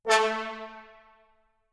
Fat_Horn_8.wav